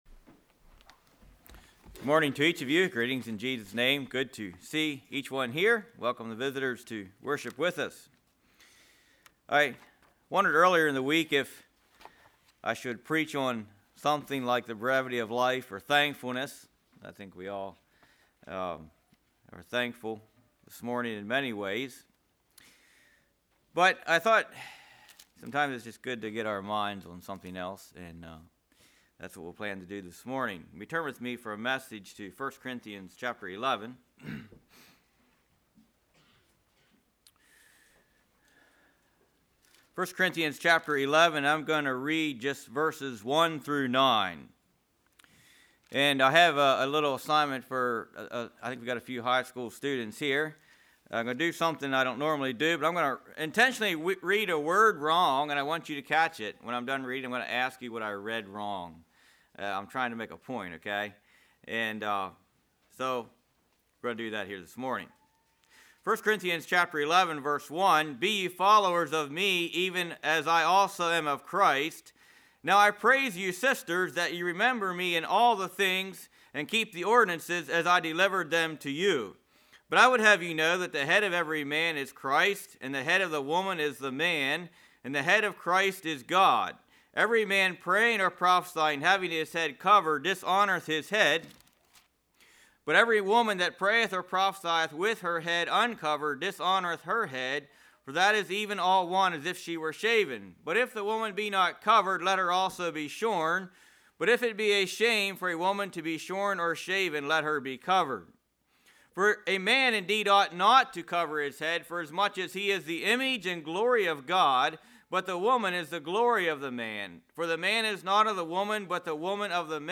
Congregation: Winchester